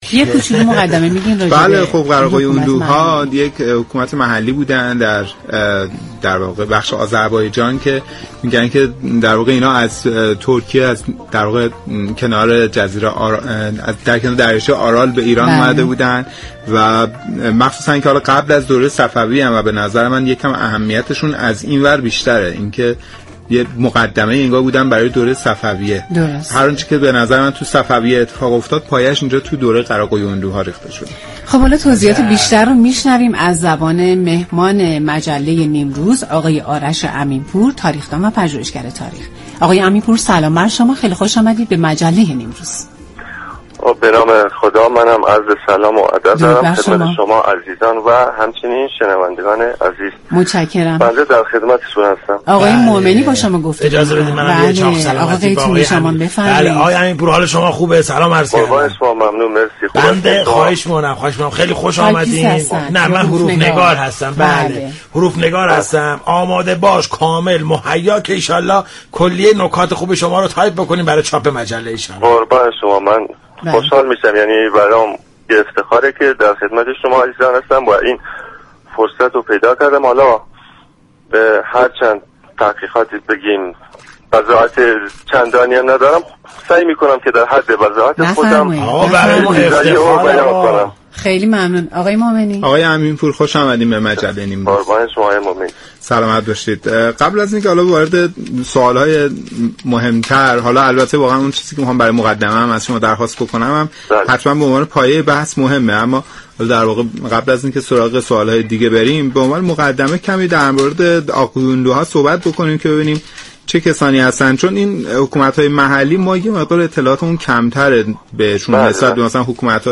در مجله ی نیم روز درباره ی دوره ی تاریخی قراقویونلوها گفتگو كرد